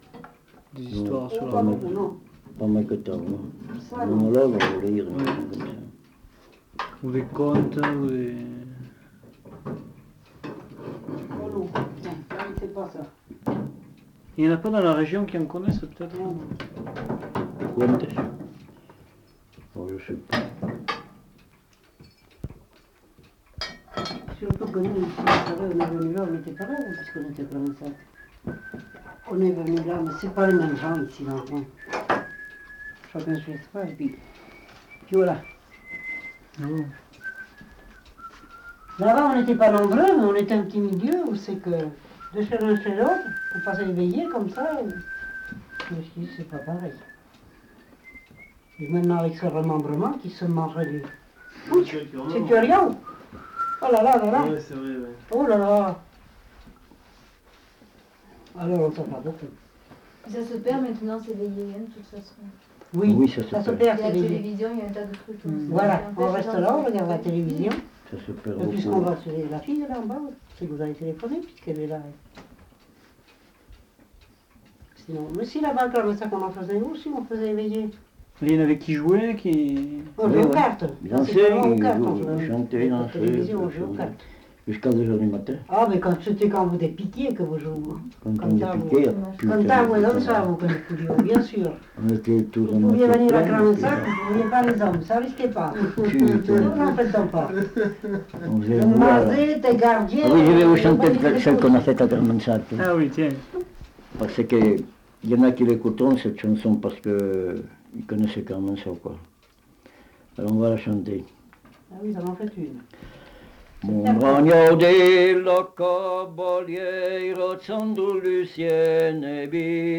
Lieu : Vernholles (lieu-dit)
Genre : chant
Effectif : 1
Type de voix : voix d'homme
Production du son : chanté
Danse : valse